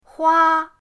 hua1.mp3